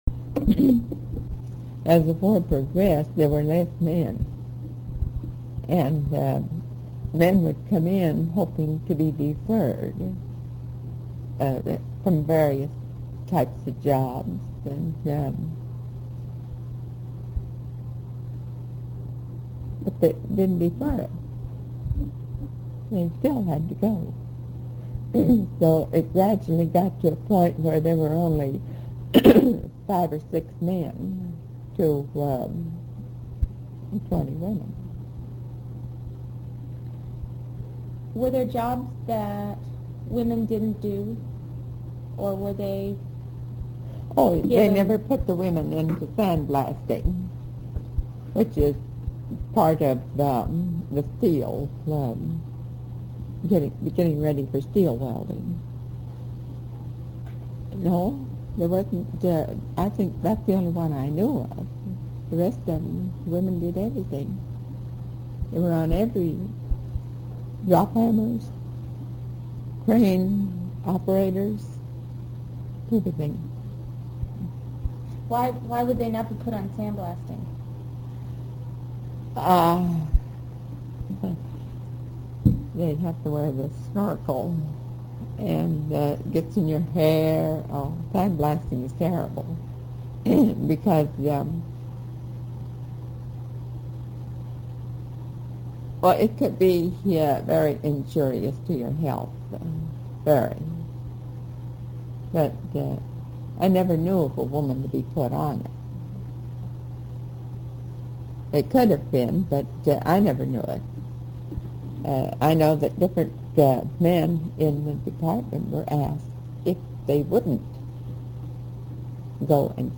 The interview was frequently disturbed by movements of the family in the background.
She spoke freely, even when the subject was noticeably difficult.